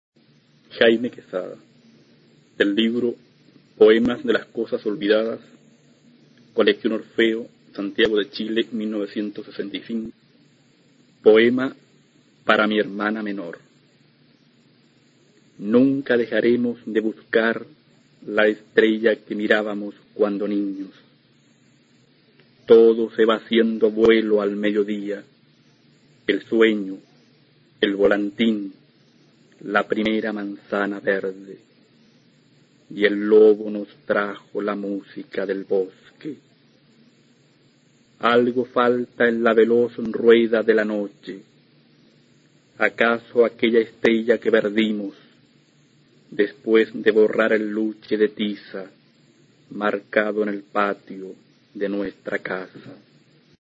A continuación se puede escuchar a Jaime Quezada, autor chileno de la Generación del 60, recitando su obra llamada Poema para mi hermana menor, del libro "Poemas de las cosas olvidadas" (1965).